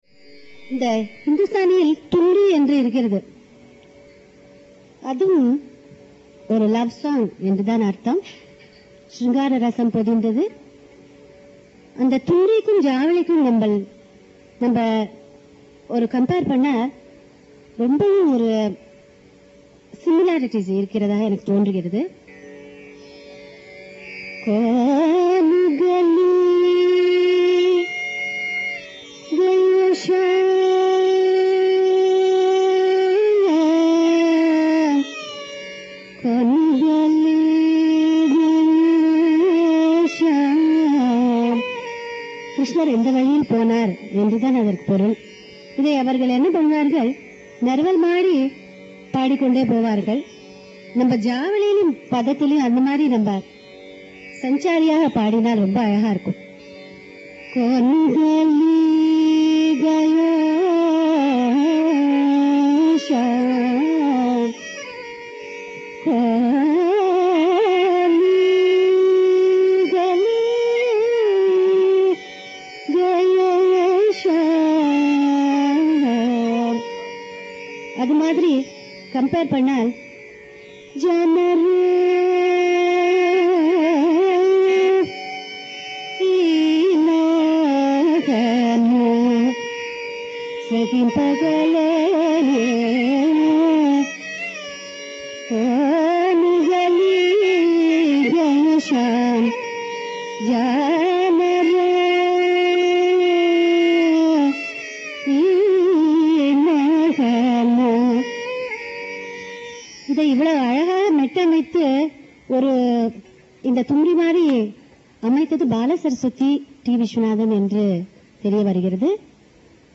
We remarked earlier that Khamas is a favorite choice for padams and jAvalis.